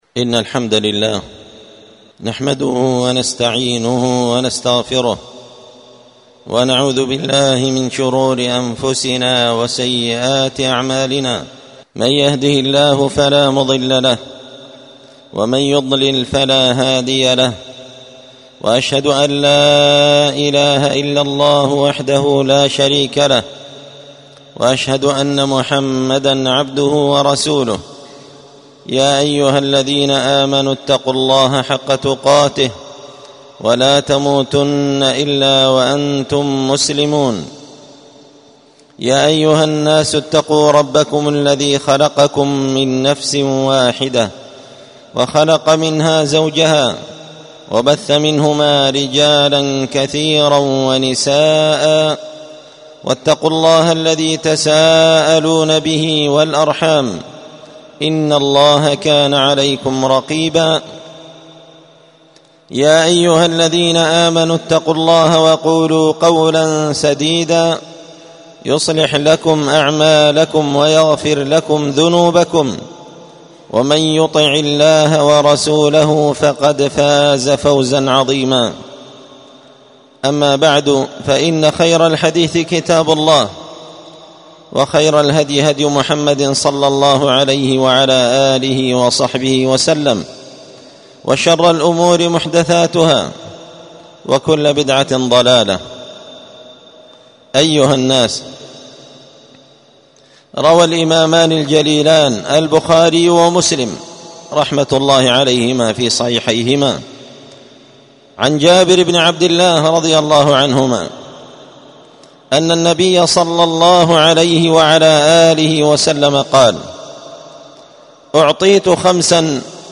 ألقيت هذه الخطبة بدار الحديث السلفية بمسجد الفرقانقشن-المهرة-اليمن تحميل…